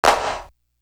Marathon Clap.wav